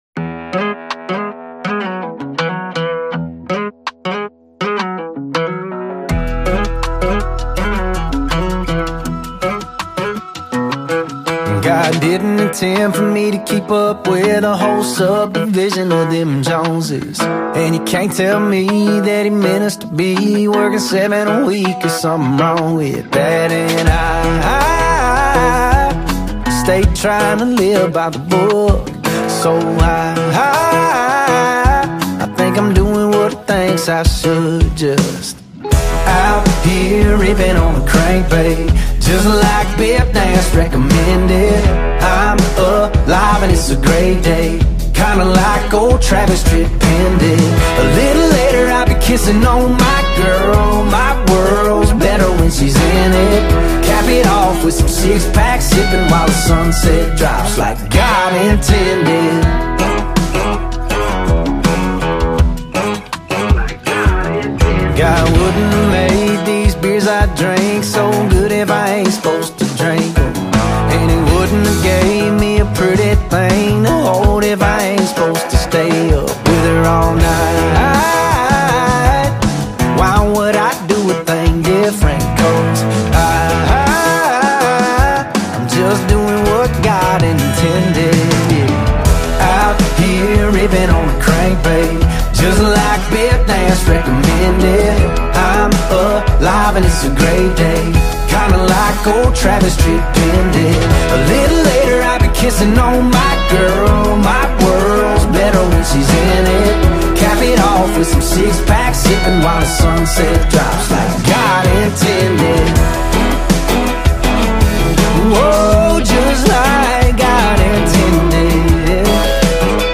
heartfelt country love song
smooth country melodies